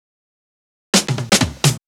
Fill 128 BPM (21).wav